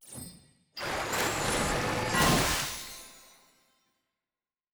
sfx-tft-skilltree-ceremony-water-division-up.ogg